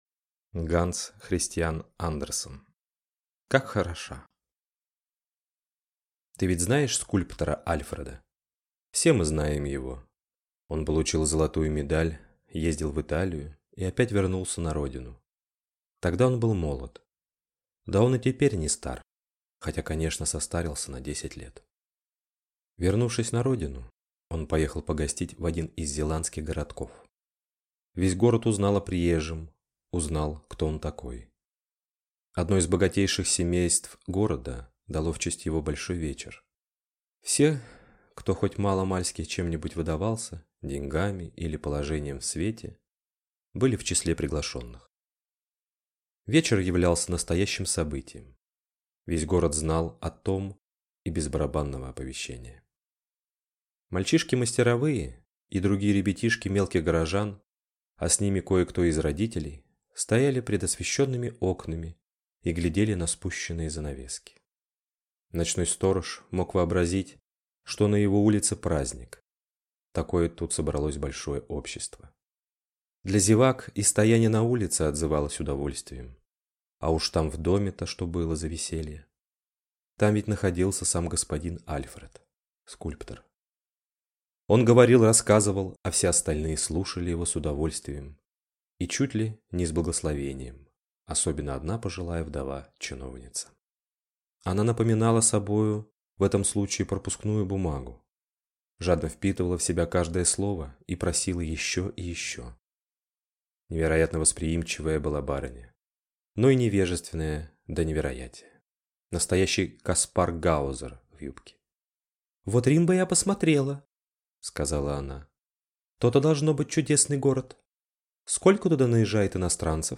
Аудиокнига Как хороша!